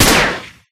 Gun1.ogg